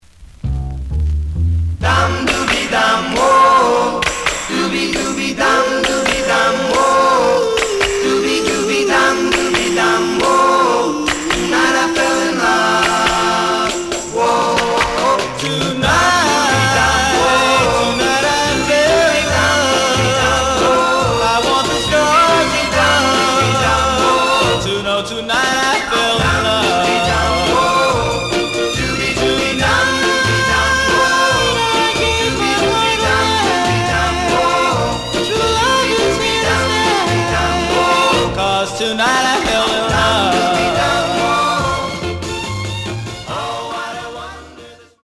The audio sample is recorded from the actual item.
●Genre: Rhythm And Blues / Rock 'n' Roll
Edge warp. But doesn't affect playing. Plays good.)